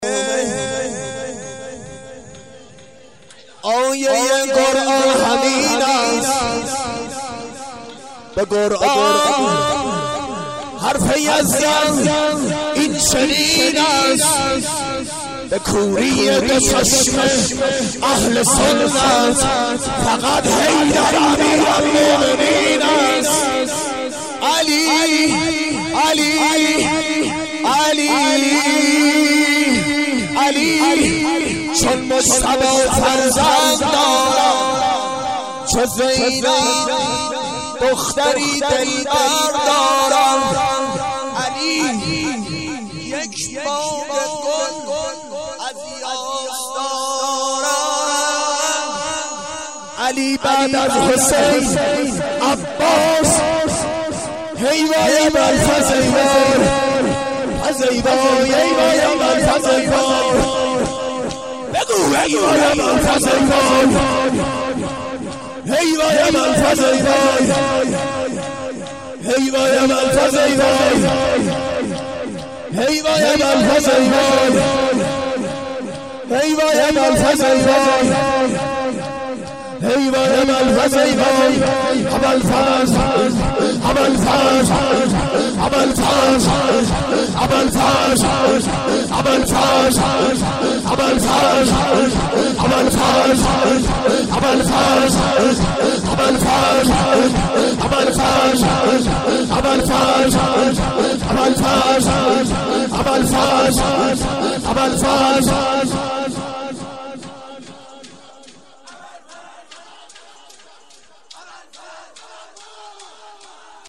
مدح حضرت علی(ع)